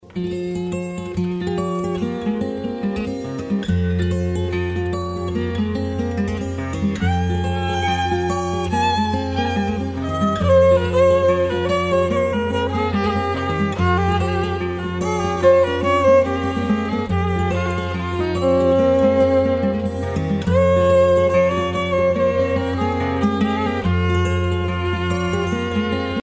danse : laridé, ridée
Pièce musicale éditée